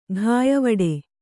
♪ ghāyavaḍe